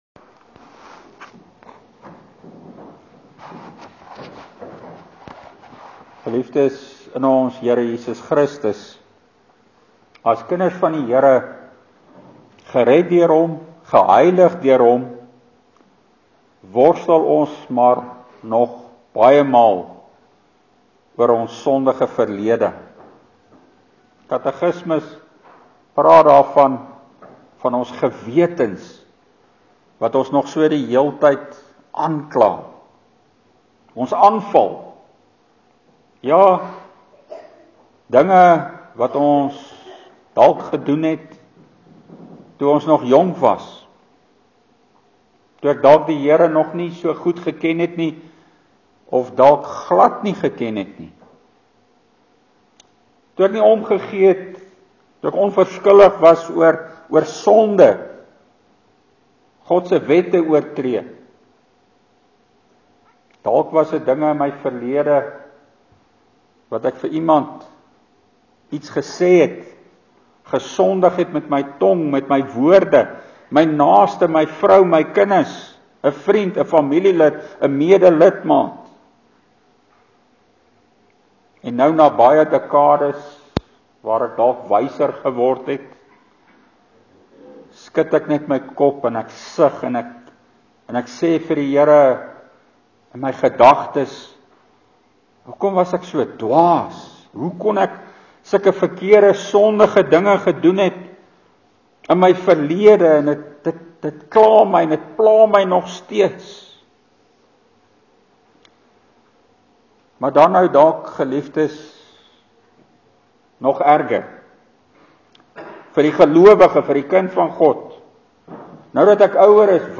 Preek: Ons enigste Troos – Die Here dink aan ons na sy goedertierenheid, en nie na ons sonde nie (Psalm 25: 6,7)